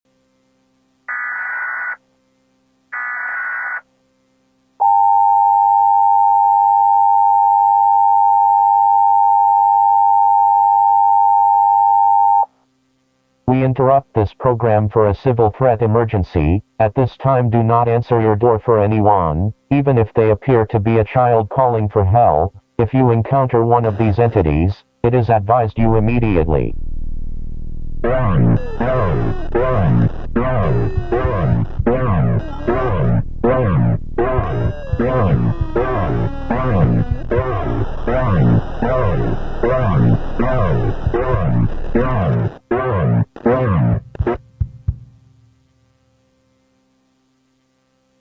Very_strange_EAS_Alert_saying_to_run